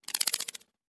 Звук вибрирующего хвоста скорпиона